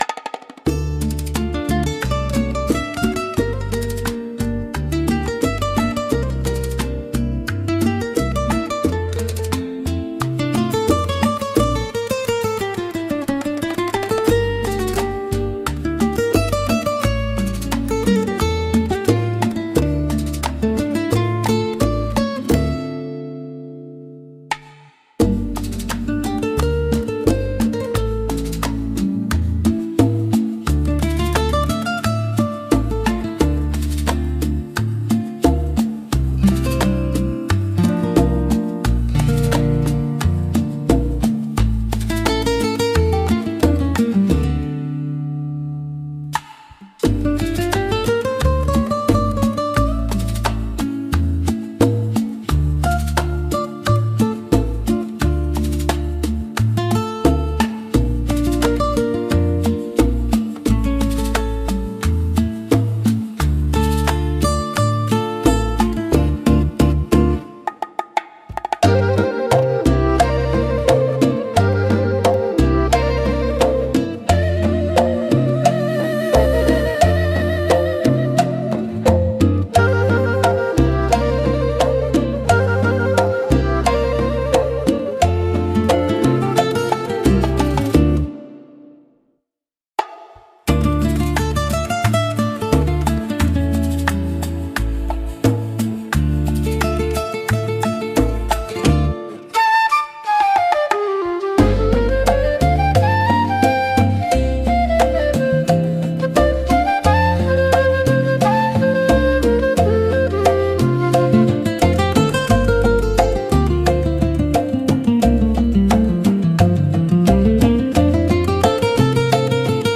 música e arranjo: IA) instrumental 7